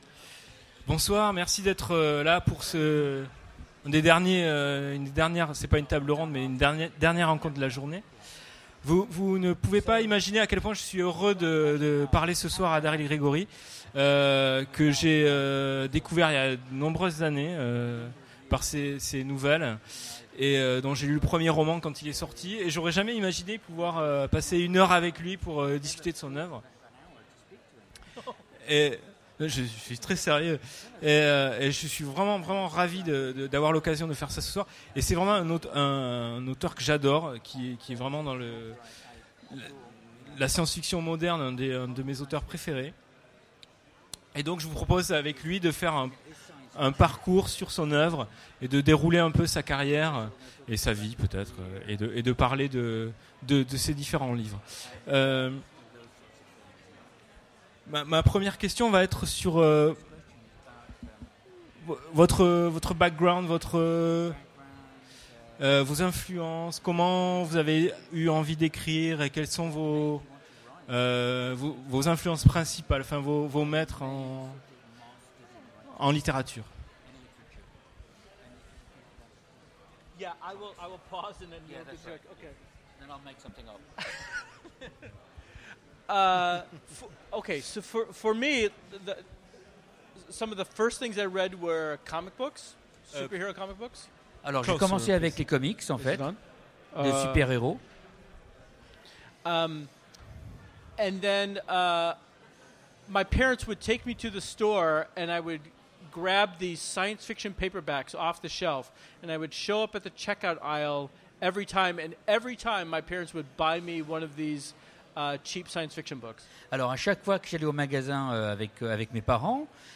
Utopiales 2015 : Rencontre avec Daryl Gregory
Aujourd'hui retour sur la venue en 2015 de Daryl Gregory en France aux Utopiales avec une conférence et une interview.